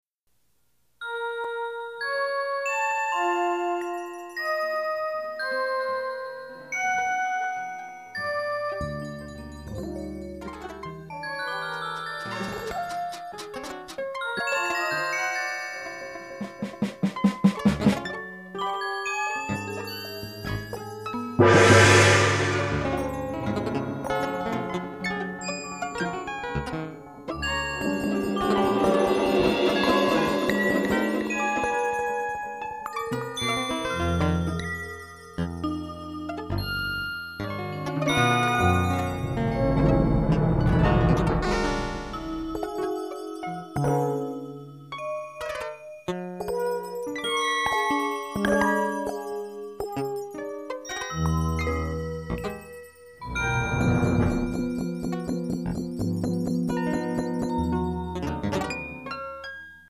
Timin (electronics)
Serially controlled pitch and rhythmic elements as well as fixed registration were used to create basic gestures.
The various steams of sound were edited further and combined digitally. Serial procedures within serial procedures become evident as the composition unfolds and this mushrooming effect becomes the primary formal design.
Timin is to be performed very softly, just at the threshold of hearing.